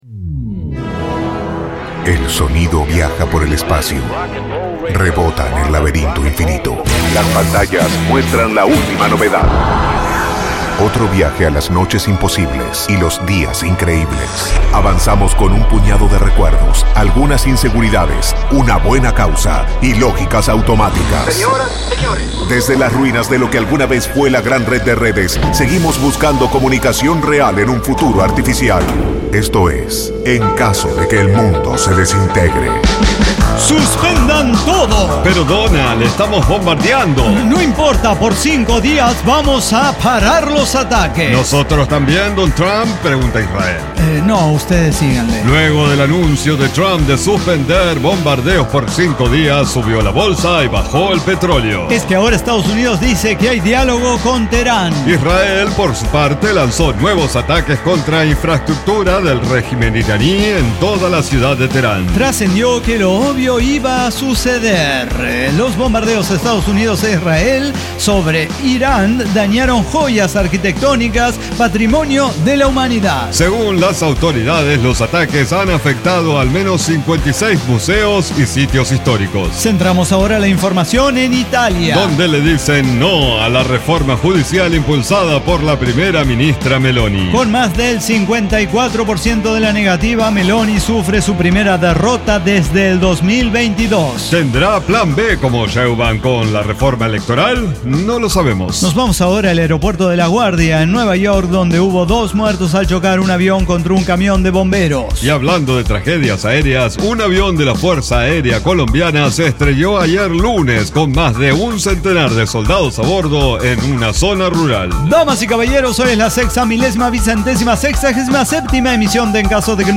Diseño, guionado, música, edición y voces son de nuestra completa intervención humana